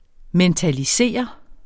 Udtale [ mεntaliˈseˀʌ ]